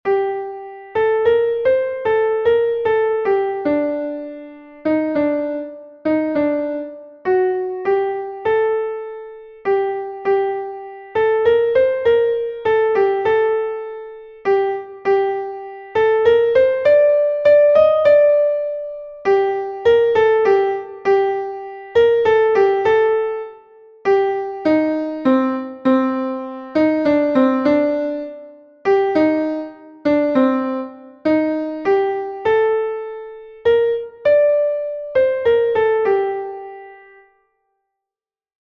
Exercise 3:  two crotchets and semiquavers  dotted crotchet and semiquaver  semiquaver and dotted crotchet
Key of G minor, two flats (B-flat and E-flat) in the key signature.
Exercise 3 keeping the beat